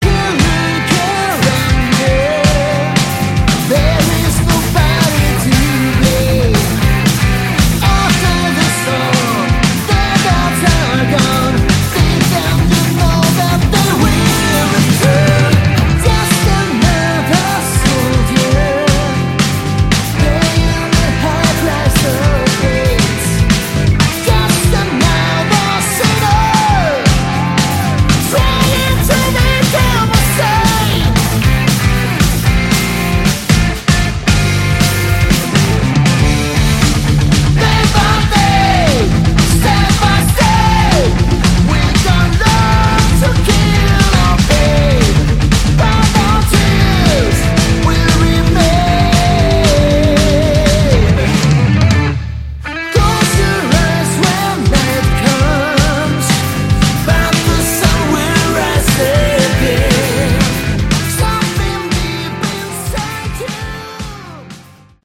Category: Hard Rock
guitar
bass
lead vocals
drums